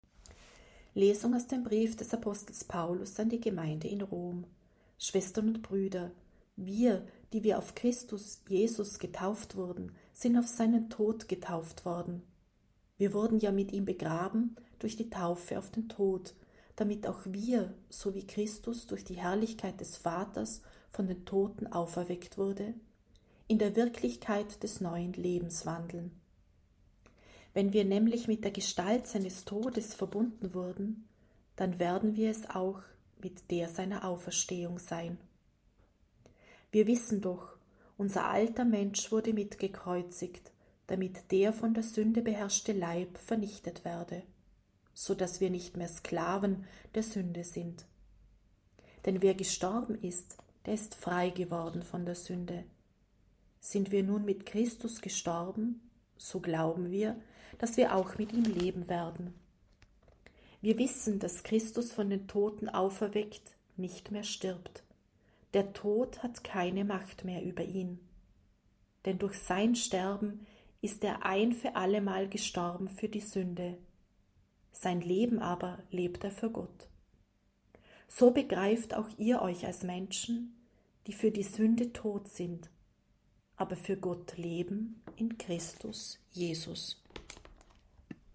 Wenn Sie den Text der 8. Lesung aus dem Brief des Apostel Paulus an die Gemeinde in Rom anhören möchten:
C-Osternacht-Epistel.mp3